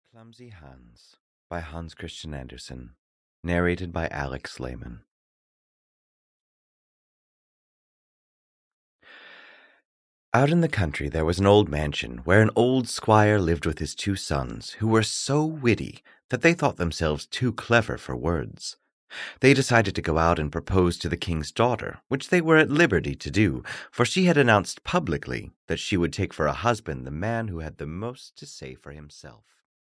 Clumsy Hans (EN) audiokniha
Ukázka z knihy